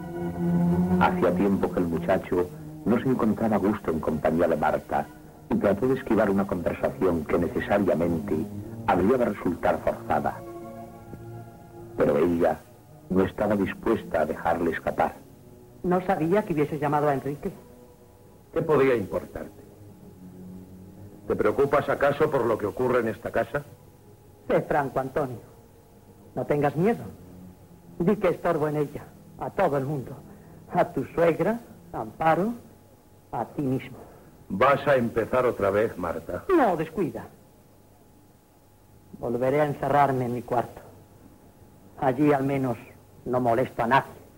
Serial radiofònic. Diàleg entre Marta i Antonio
Ficció